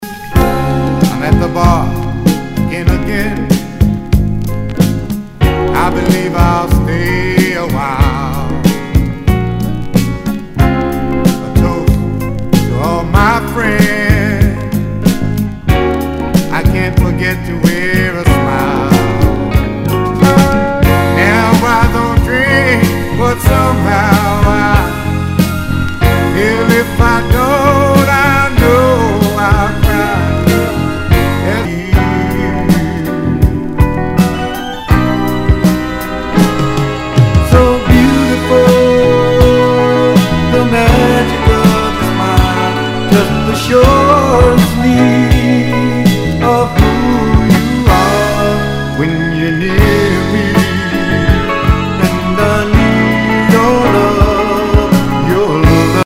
SOUL/FUNK/DISCO
ナイス！メロウ・モダン・ソウル!！
全体にチリノイズが入ります